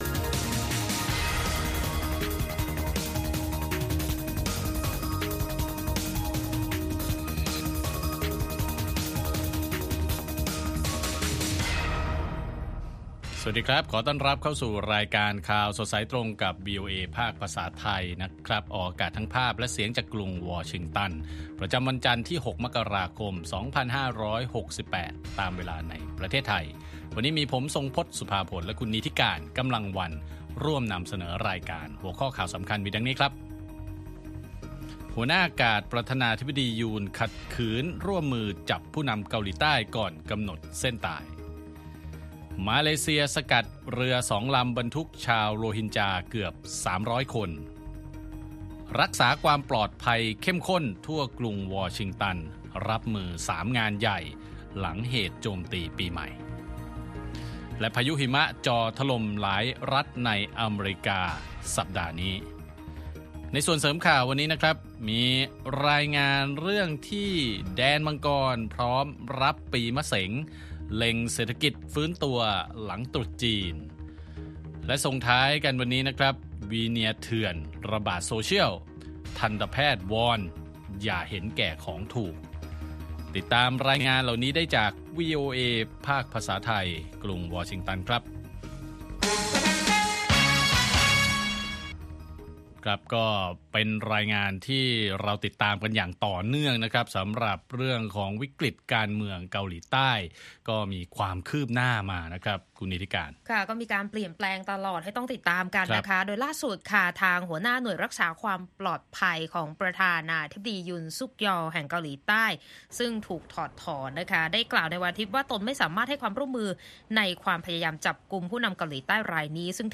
ข่าวสดสายตรงจากวีโอเอไทย จันทร์ ที่ 6 ม.ค. 68